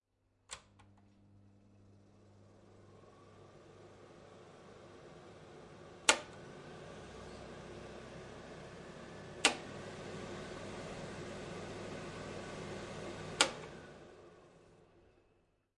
厨房 " 5个抽油烟机
描述：引擎罩